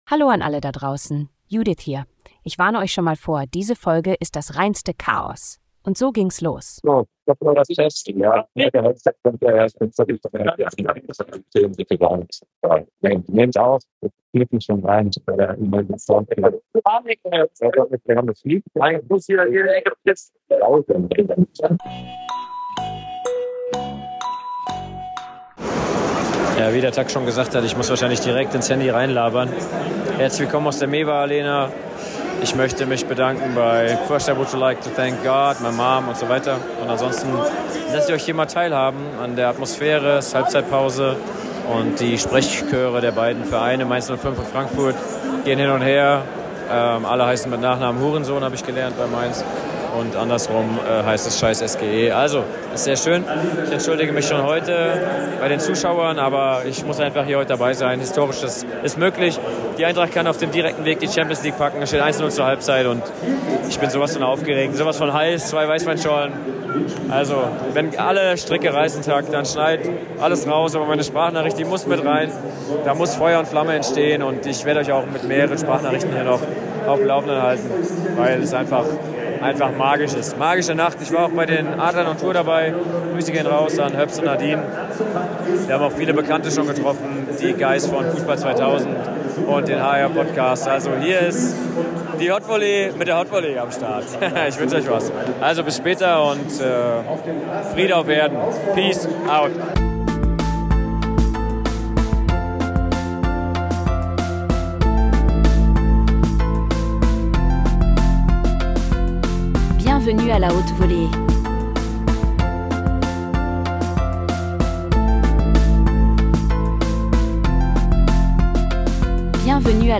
Klangqualität? Überbewertet. Dazu nehmen wir unseren Nike Container und tauchen tief in die Supply Chain ein.